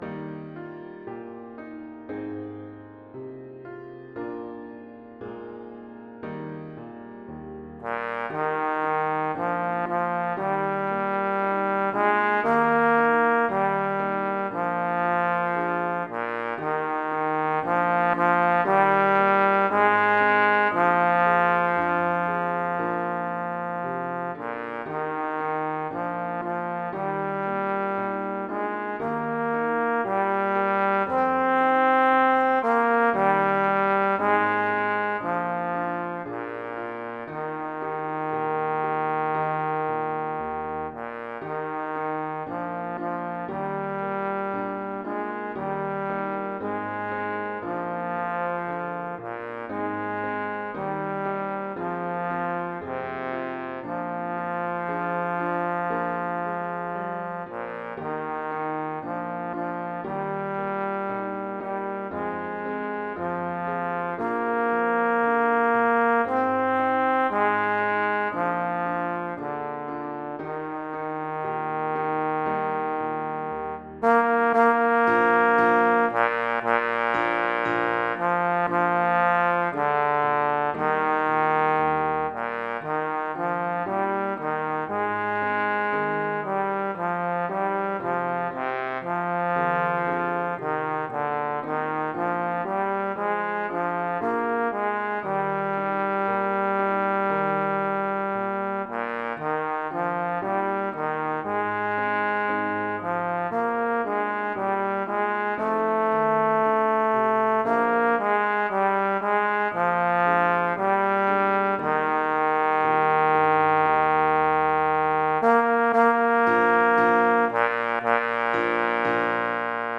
Voicing: Trombone